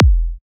edm-kick-53.wav